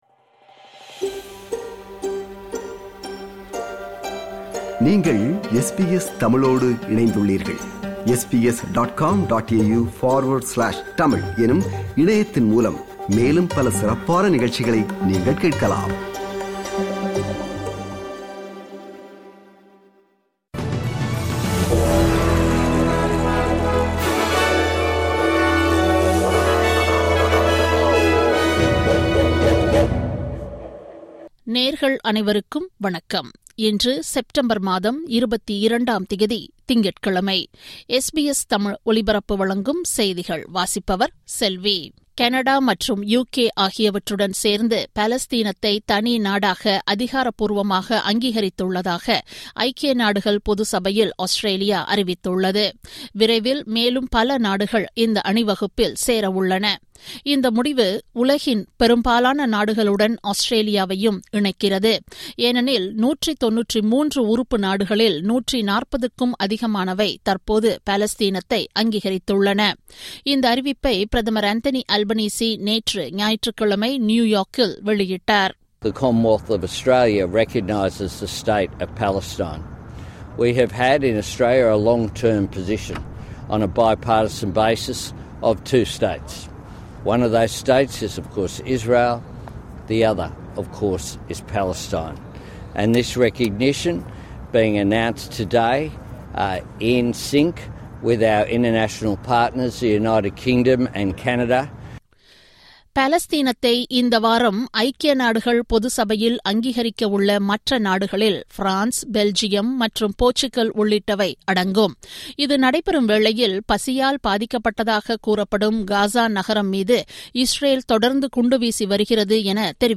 SBS தமிழ் ஒலிபரப்பின் இன்றைய (திங்கட்கிழமை 22/09/2025) செய்திகள்.